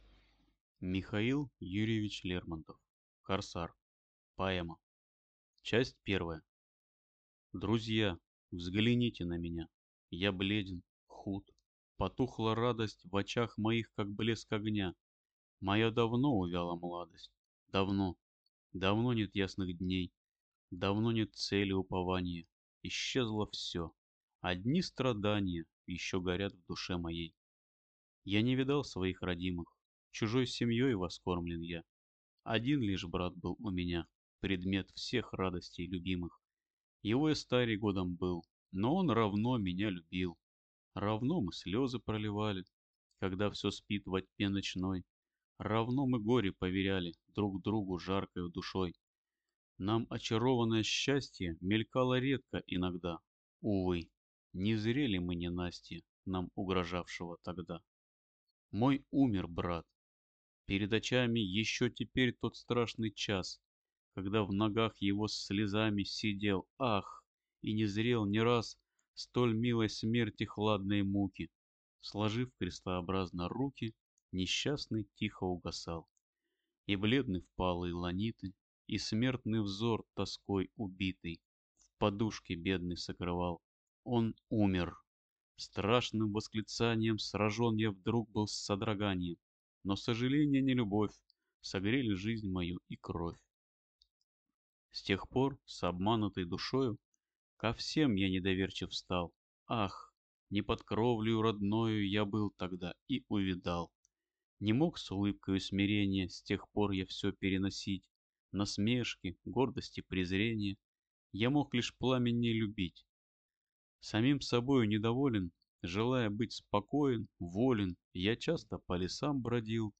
Аудиокнига Корсар | Библиотека аудиокниг
Прослушать и бесплатно скачать фрагмент аудиокниги